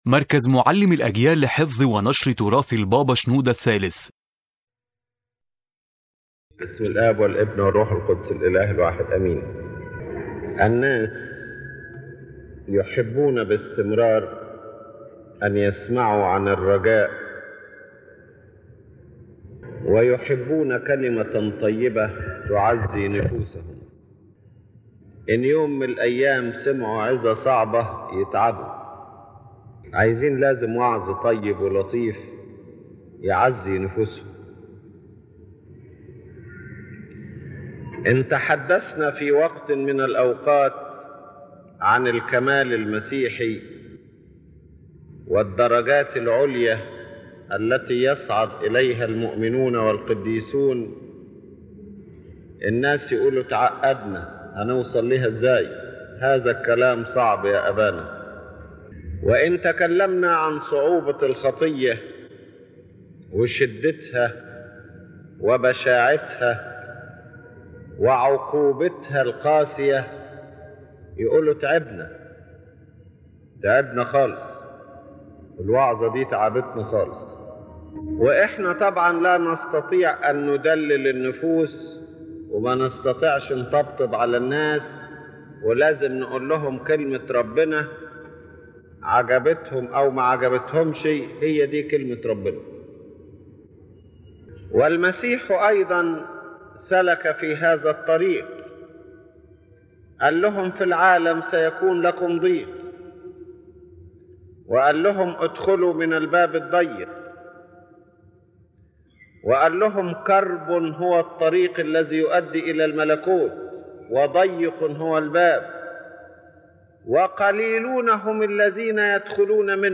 Main Message of the Sermon: Pope Shenouda III explains that the spiritual life is not a journey taken alone, but one surrounded by the continuous help of God. Every call to holiness or perfection is made possible only through the power of God and the grace of His Holy Spirit dwelling within us.